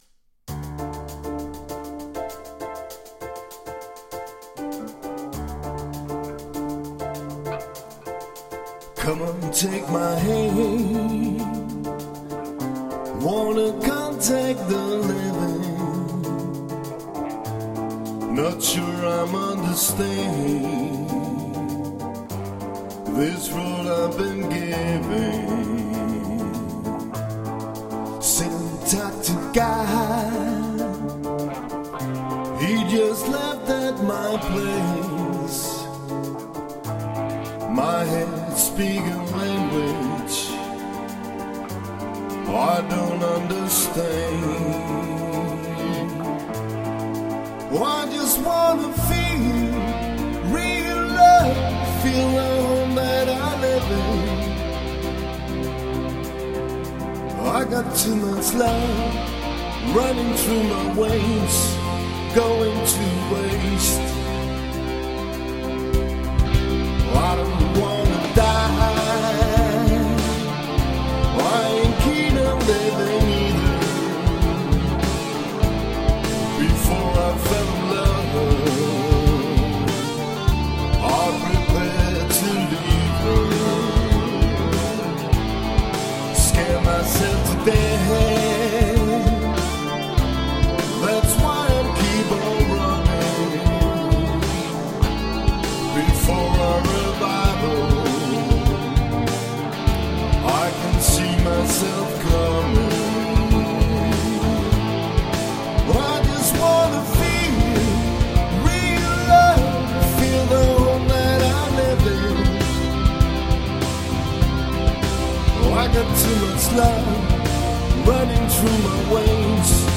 • Coverband
• Duo eller trio